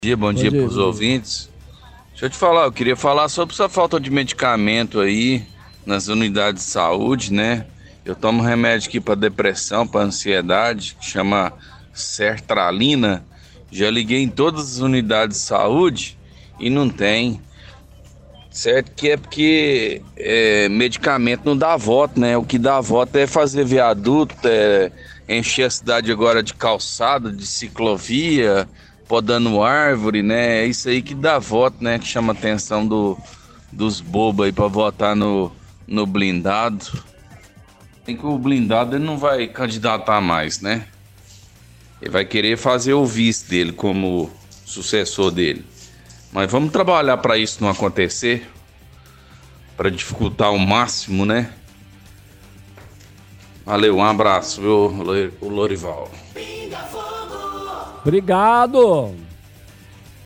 – Ouvinte reclama sobre falta de medicamento nas unidades de saúde, ele diz que toma remédio para condições psiquiátricas, liga para as unidades de saúde e nenhuma tem. Fala sobre obras que dão voto, mas medicamentos não.